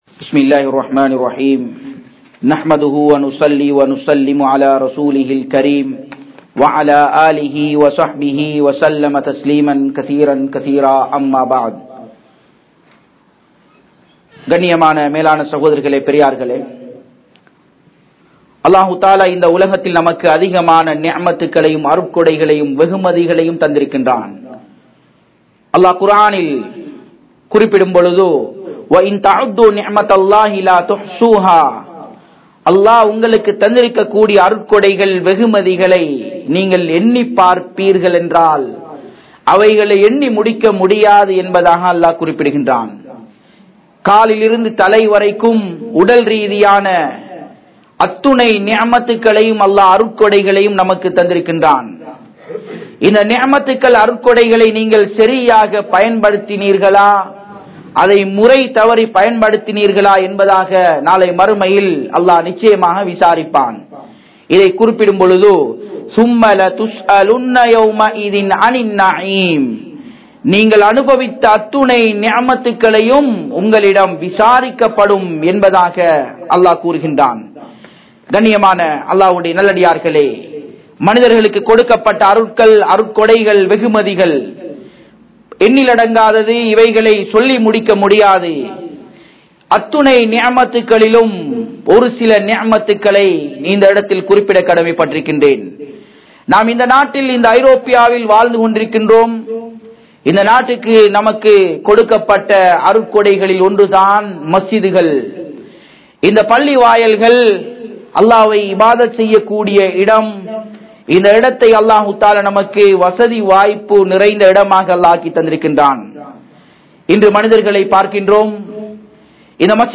Europe vin Saathanaiyaalarhalaaha Maarungal (ஐரோப்பாவின் சாதனையாளர்களாக மாறுங்கள்) | Audio Bayans | All Ceylon Muslim Youth Community | Addalaichenai